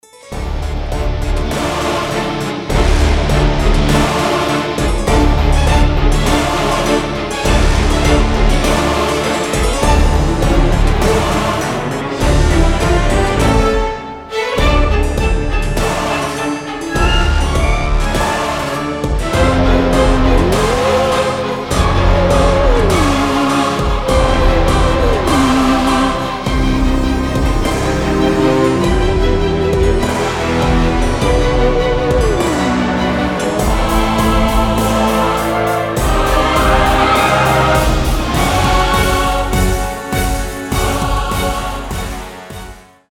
• Качество: 320, Stereo
без слов
мрачные
оркестр
орган